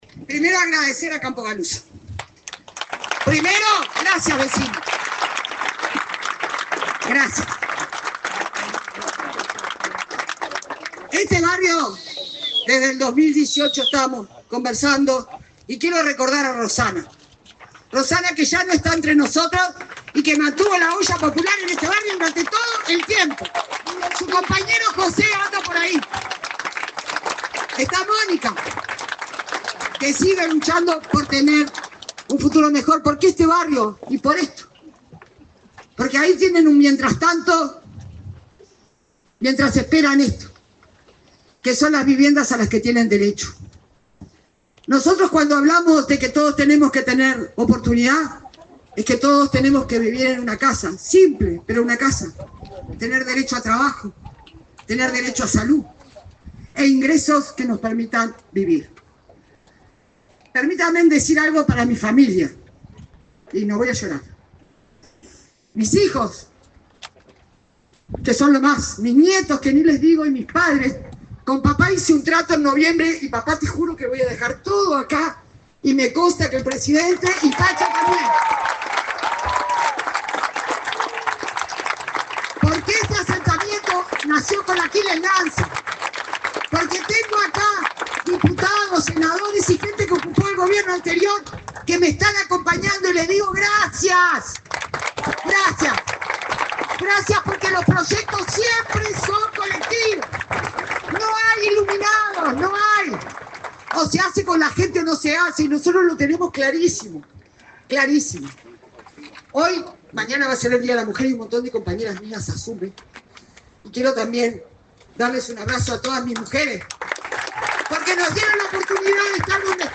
Palabras de la ministra de Vivienda, Cecilia Cairo